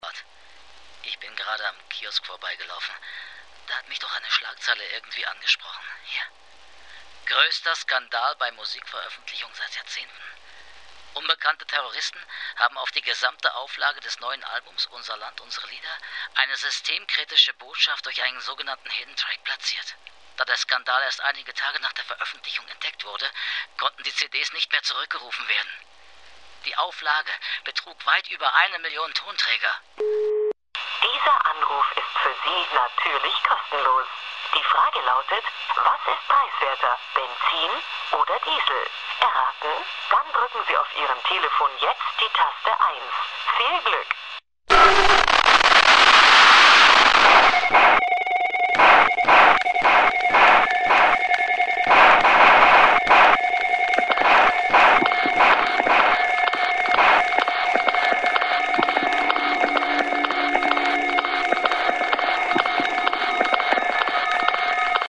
Also Tuten, Spulen, Rauschen, Piepen, Knacksen und ähnliches.
Ausschnitte der Radiofassung: